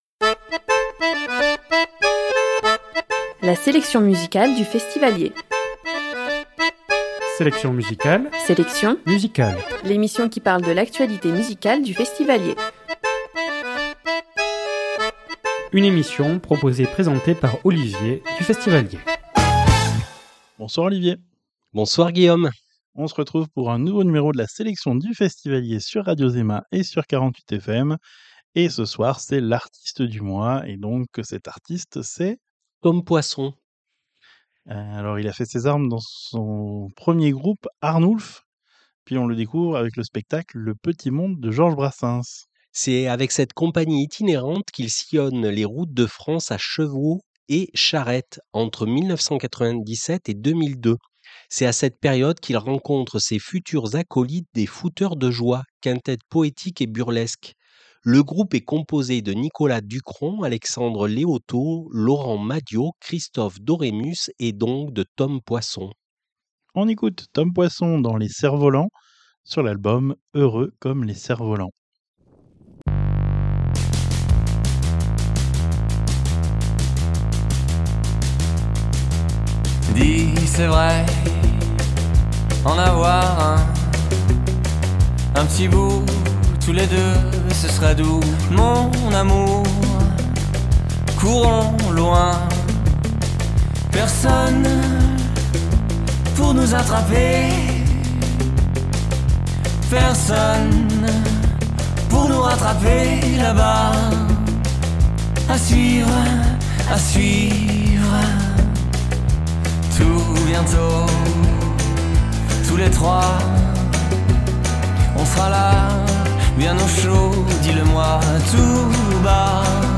Émission du vendredi 14 mars 2025 à 19hRediffusion le dimanche suivant à 21h